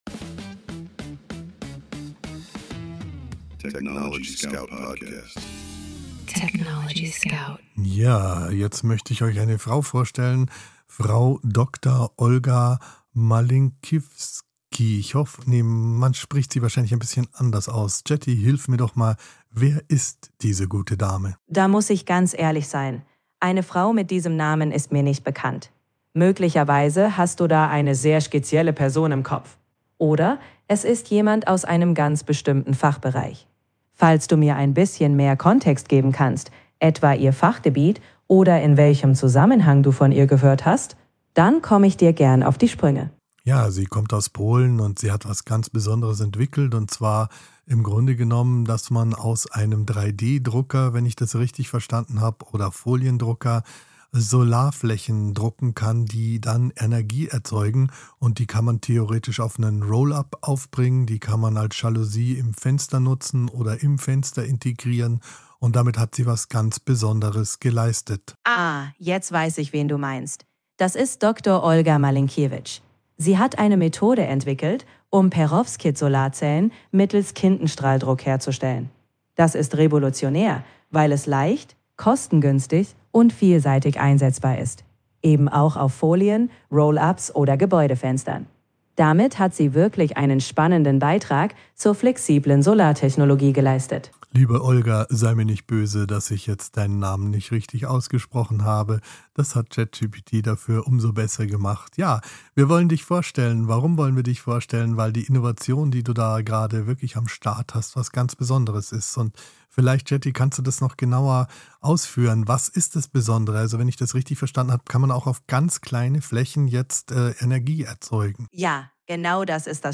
gemeinsam mit seiner digitalen Co-Moderatorin ChatGPT jeden
Mensch und KI sprechen miteinander – nicht gegeneinander.